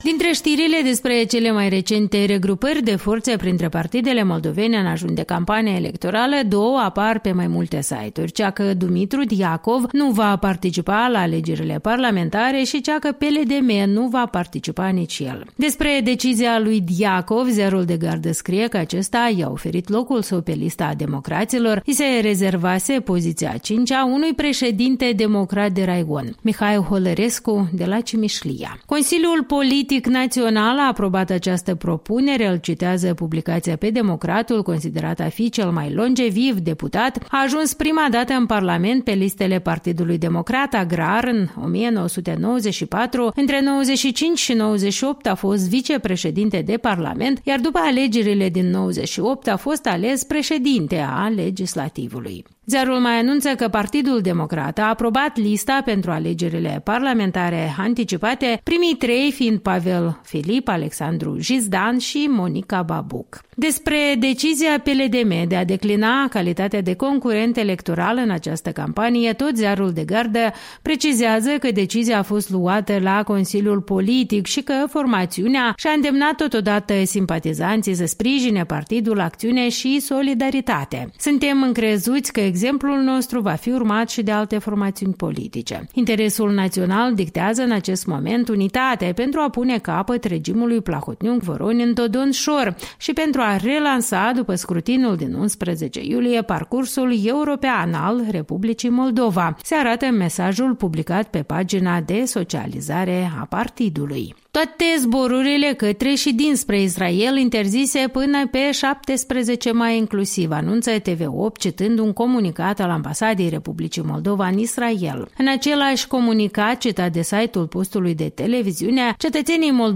Revista matinală a presei la radio Europa Liberă